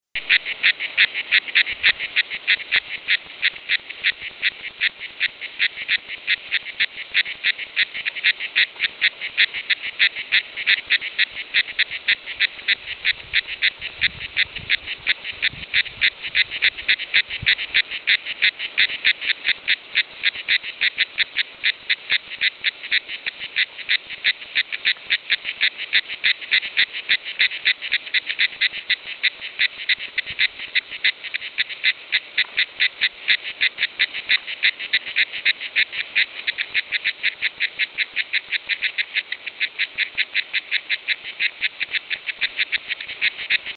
Cigale grise Cicada orni
NB : effrayée au départ le chant enregistré est timide lent et peu agressif, mais il augmente au fur et à mesure de la prise de confiance de la cigale avec parfois des pics très sonores.